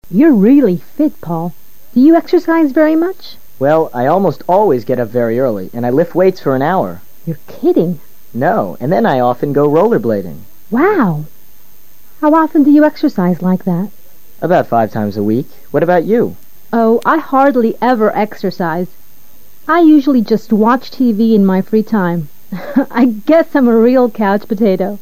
LISTENING TEST 4/16: Ahora, basándote en los comentarios del diálogo anterior, selecciona la opción más adecuada para cada pregunta.